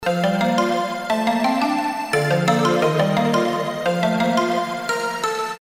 • Качество: 320, Stereo
мелодичные
без слов
электронные